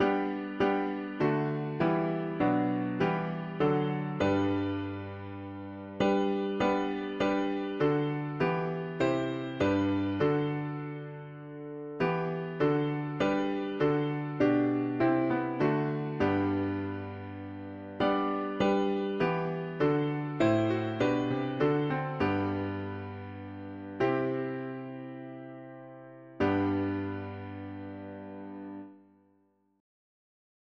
Key: G major
Amen Alternate words Let there be light Tags english french spanish german christian 4part chords